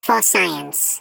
Sfx_tool_spypenguin_vo_take_picture_04.ogg